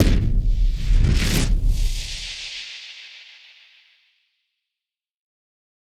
BF_HitSplosionB-05.wav